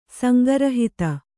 ♪ sanga rahita